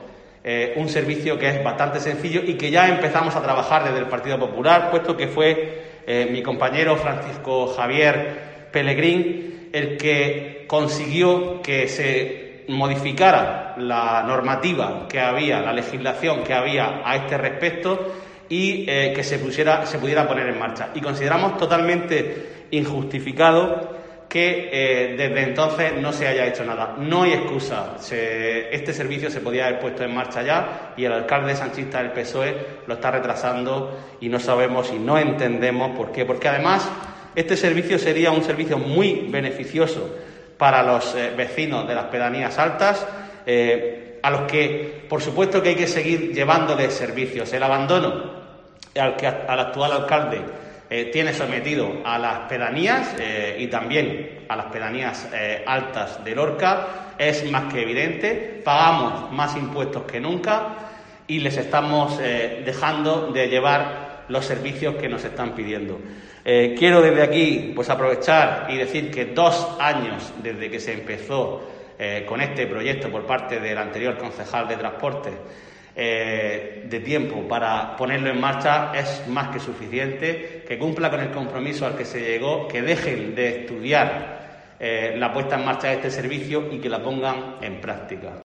Juan Miguel Bayonas, edil del PP sobre Taxi Rural